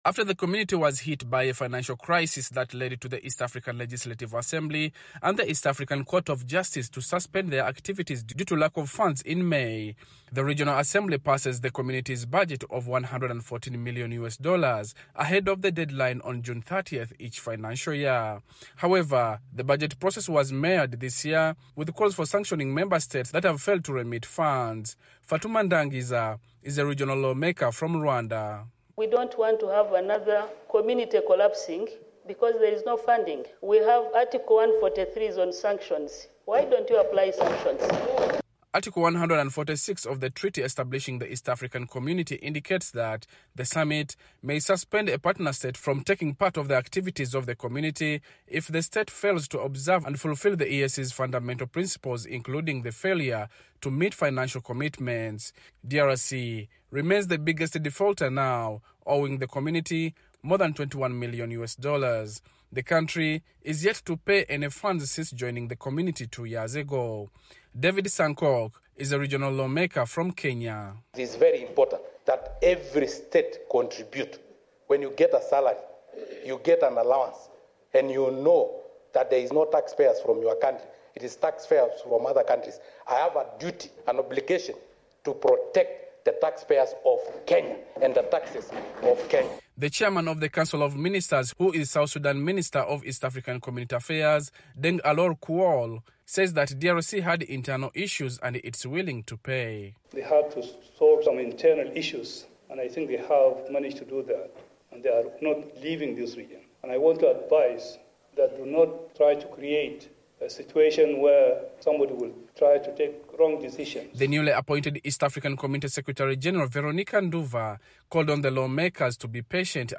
reports from Arusha.